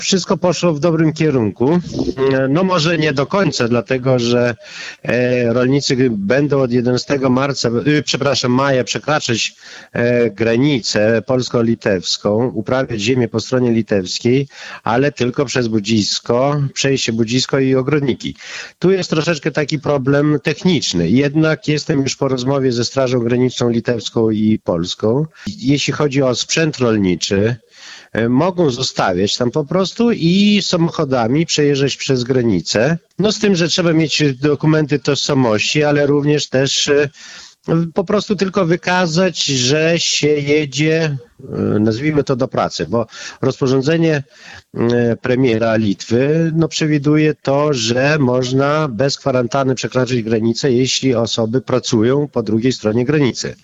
Szczegóły przedstawił dziś w Radiu 5 Witold Liszkowski, wójt Puńska, który od początku zabiegał o ułatwienie przejazdu.